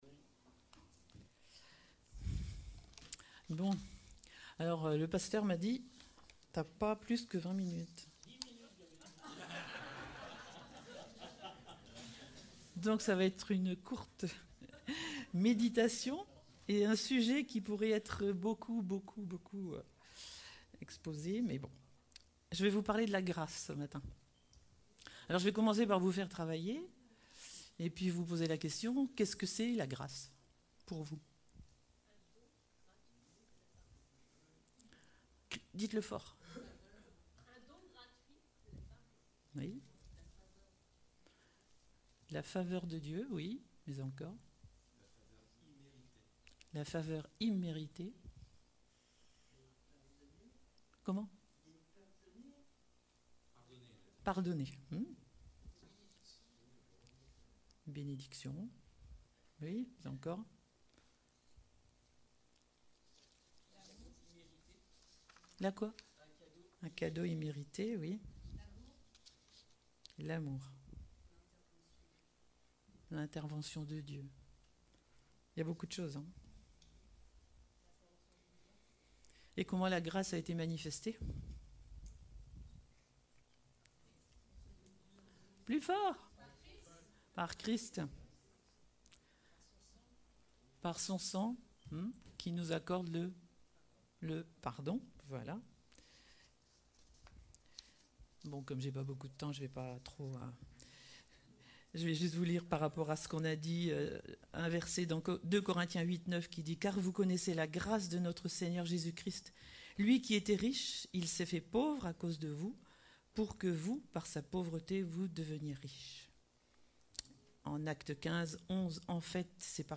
Type De Service: Culte Thèmes: Grâce , Relations fraternelles , Vie d'église « Prêts à obéir au Seigneur ?